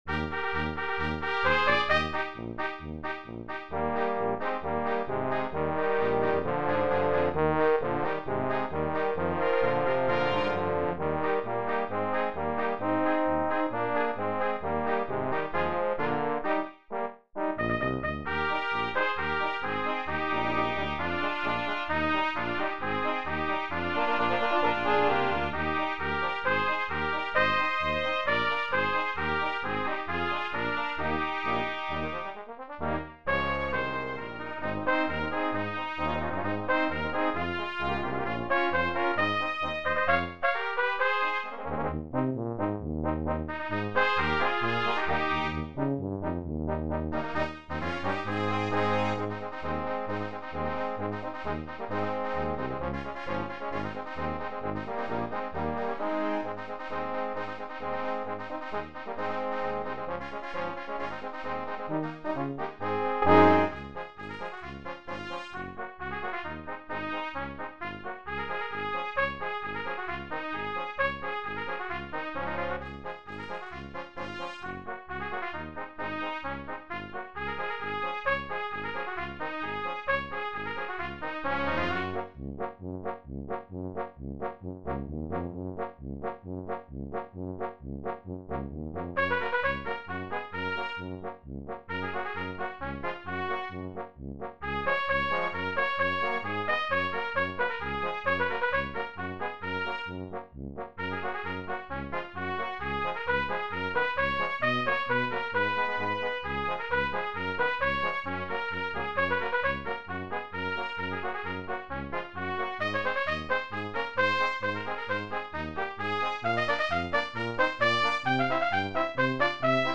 für Blechbläserquintett.
Egerländer Weihnachtspotpourri, fetzige Sammlung
2 Trompeten in B
Tenorhorn in B | Horn in F
Bariton | Posaune
Tuba